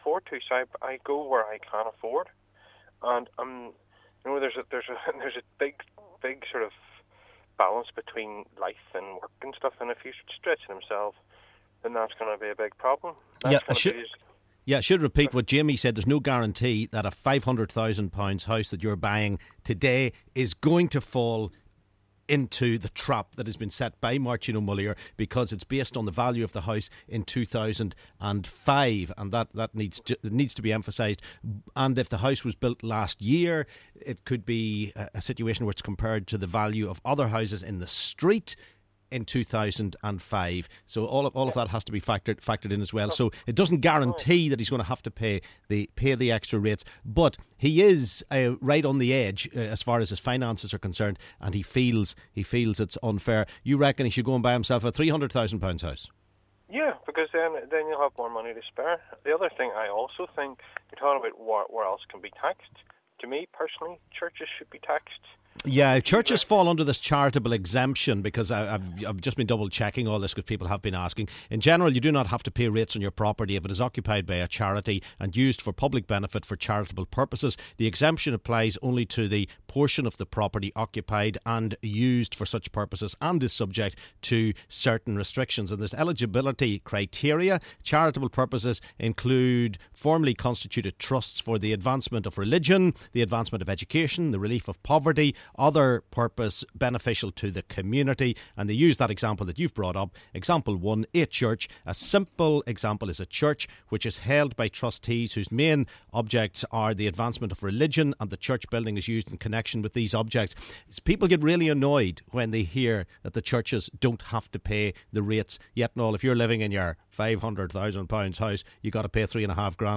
LISTEN: Callers react to Rates Changes